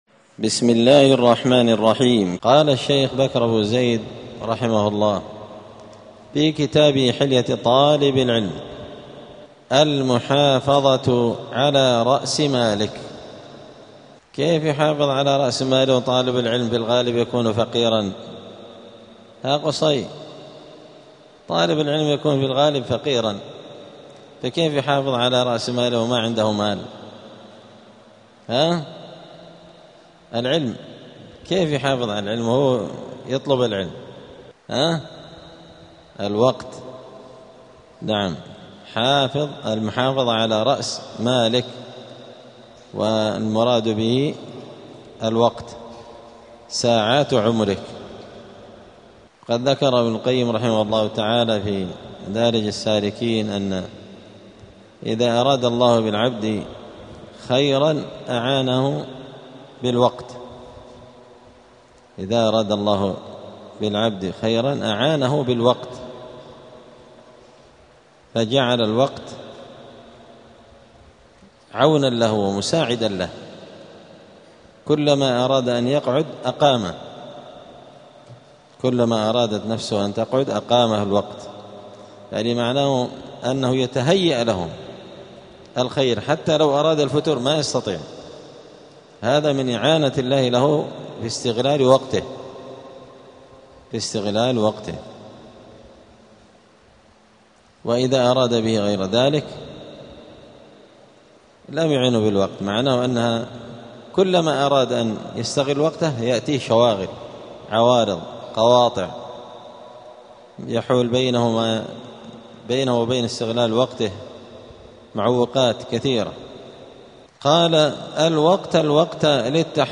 الجمعة 15 شوال 1447 هــــ | الدروس، حلية طالب العلم، دروس الآداب | شارك بتعليقك | 3 المشاهدات